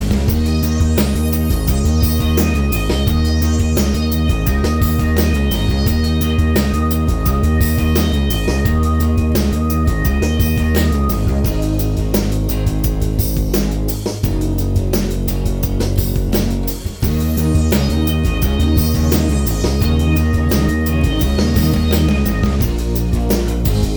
Minus Lead Guitar Indie / Alternative 4:47 Buy £1.50